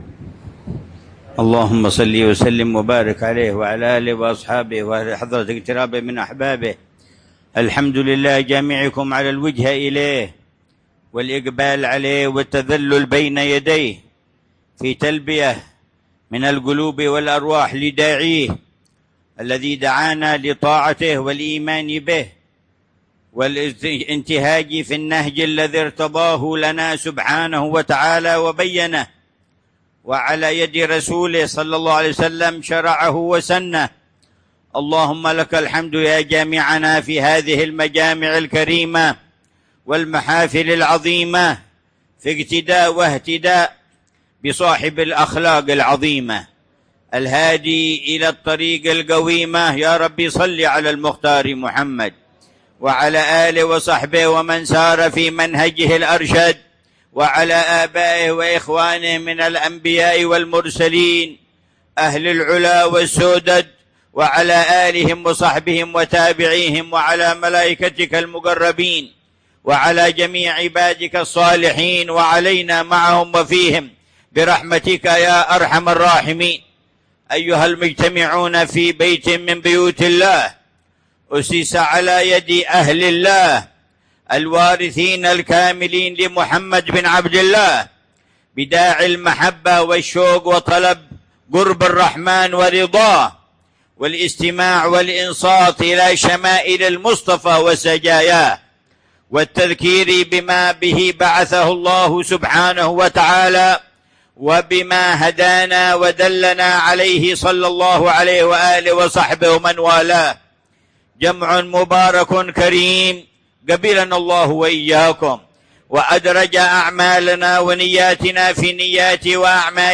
محاضرة العلامة الحبيب عمر بن محمد بن حفيظ في جامع الشيخ أبي بكر بن سالم بمنطقة عينات، ضحى الأربعاء 18 ربيع الأول 1447هـ بعنوان :